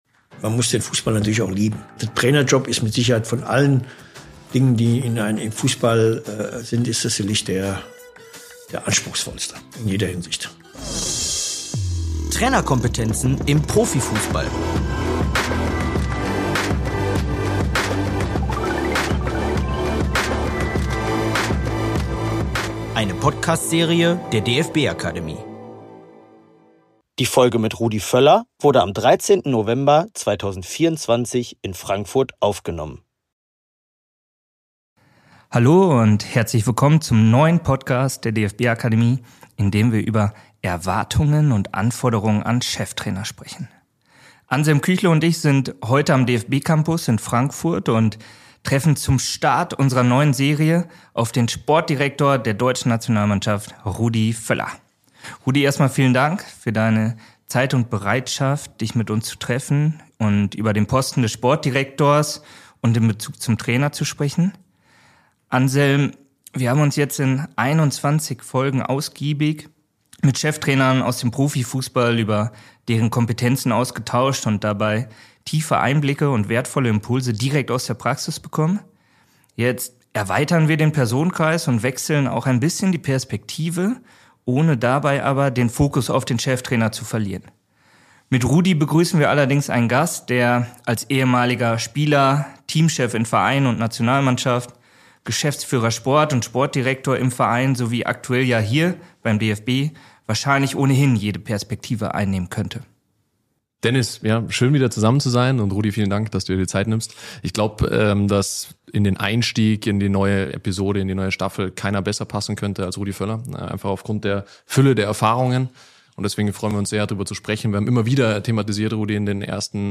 Die Folge wurde aufgenommen am 13.11.2024 am DFB-Campus in Frankfurt.